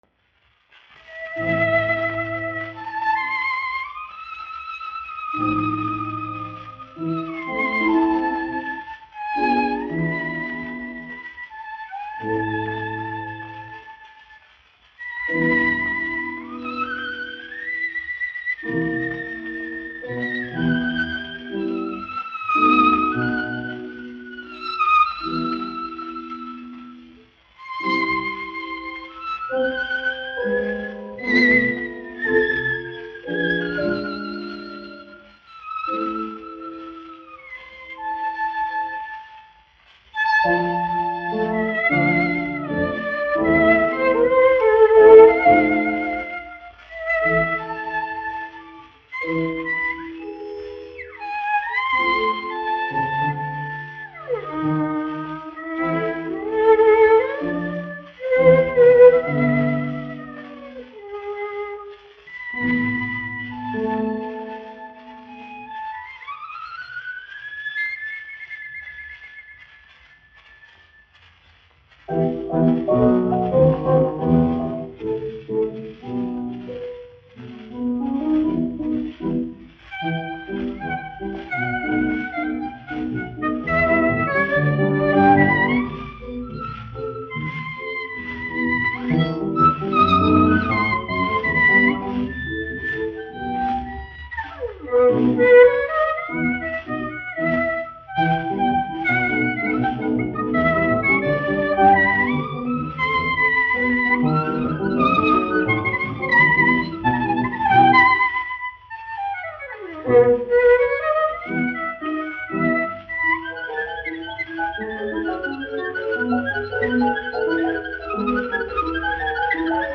1 skpl. : analogs, 78 apgr/min, mono ; 25 cm
Vijoles un klavieru mūzika
Skaņuplate